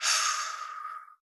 sighA.wav